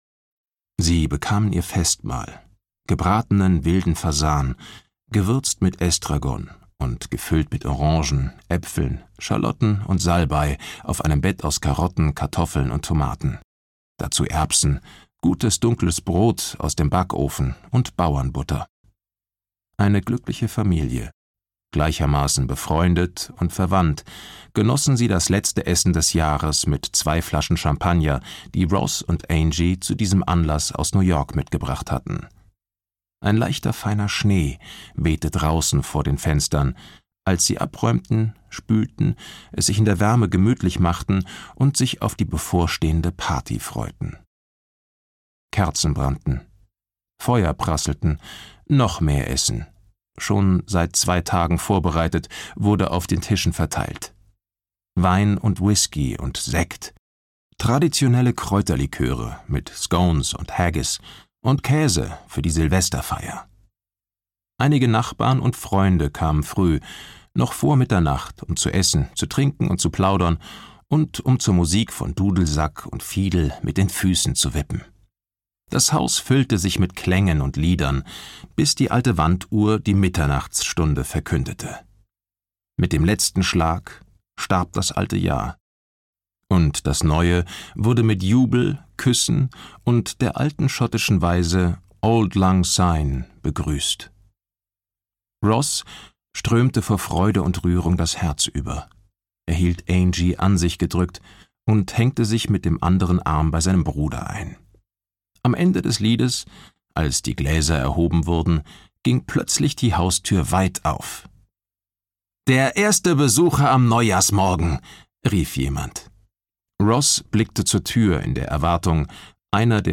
Schattenmond (DE) audiokniha
Ukázka z knihy
• InterpretGötz Otto